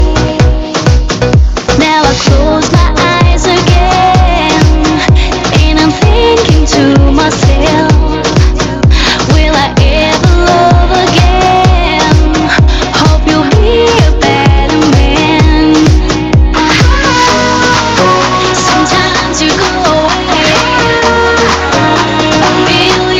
Reduced quality: Yes